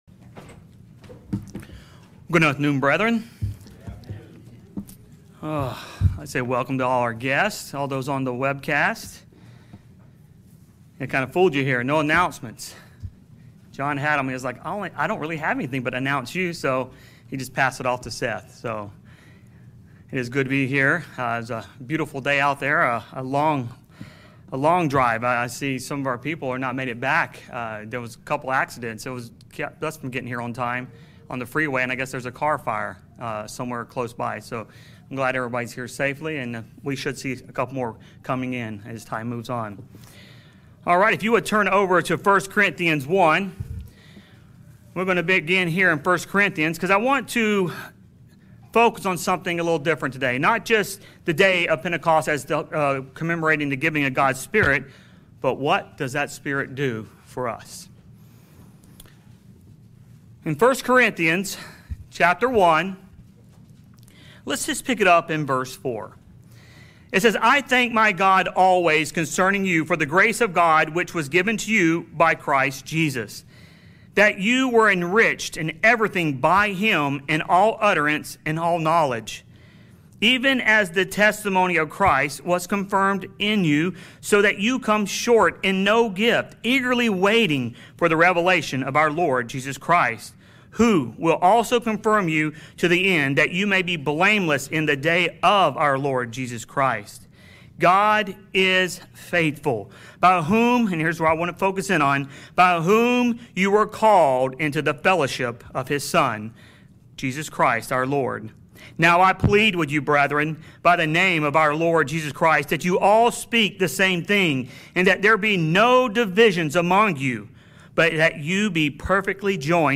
Sermons
Given in North Canton, OH